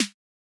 AIR Snare.wav